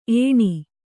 ♪ ēṇi